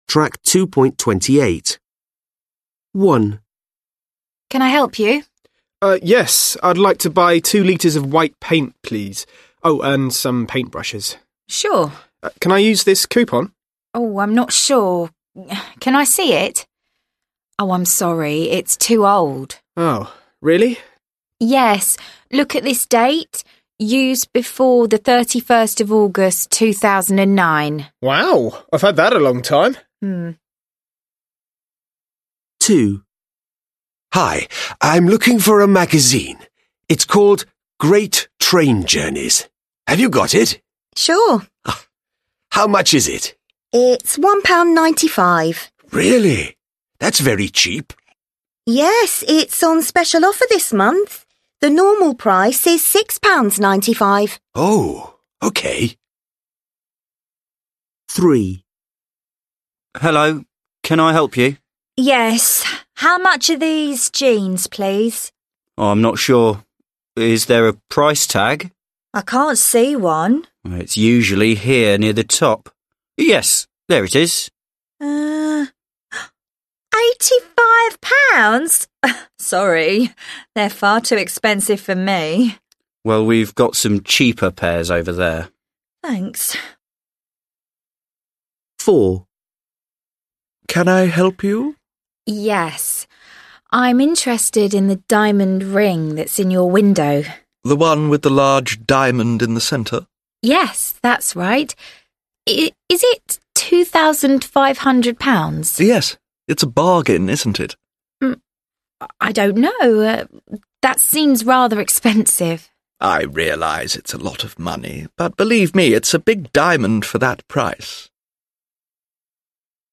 6 (trang 71 Tiếng Anh 10 Friends Global) Listen to four dialogues.